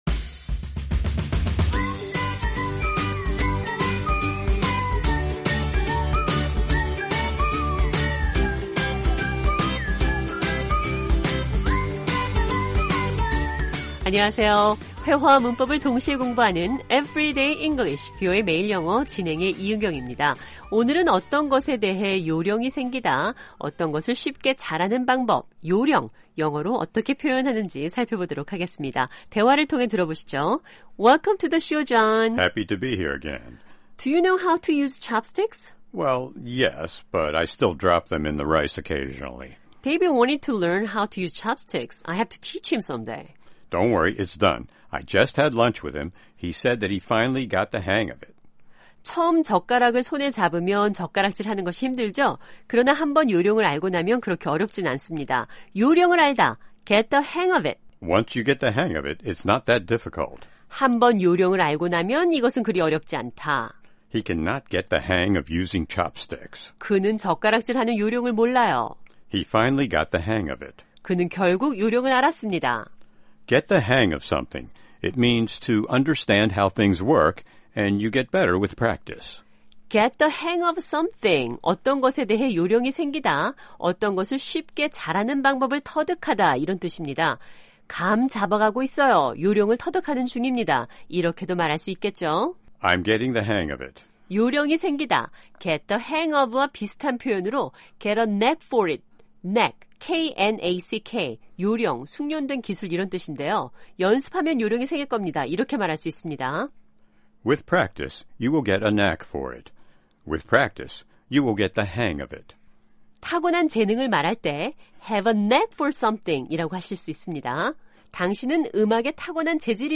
어떤것을 쉽게 잘 하는 방법 요령을 영어로 어떻게 표현하는지 살펴보겠습니다. 대화를 통해 들어보시죠.